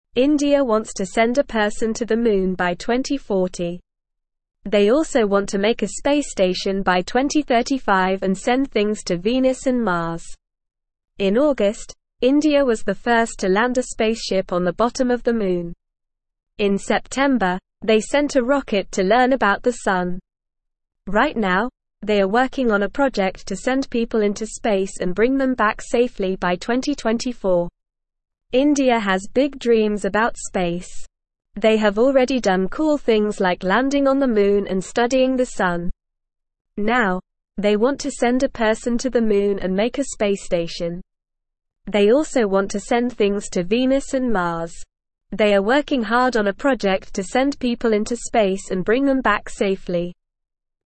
Normal
English-Newsroom-Beginner-NORMAL-Reading-Indias-Big-Space-Dreams-Moon-Sun-and-More.mp3